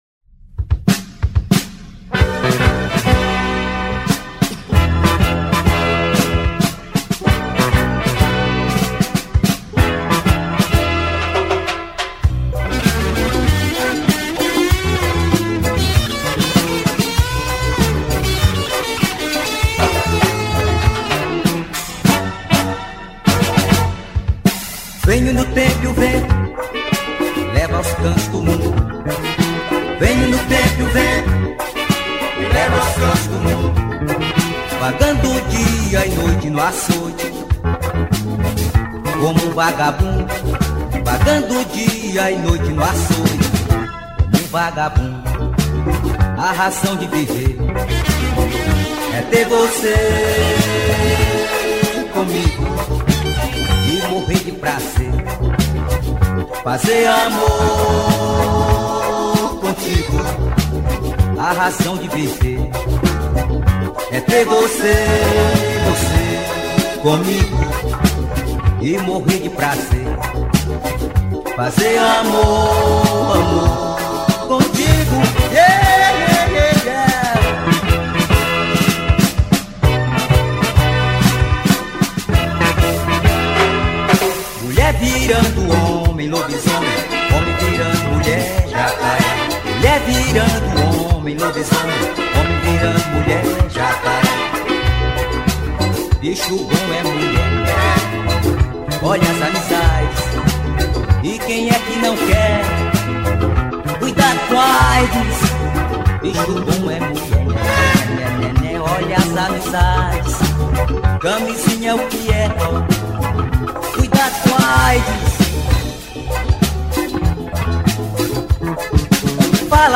2640   04:18:00   Faixa: 13    Rock Nacional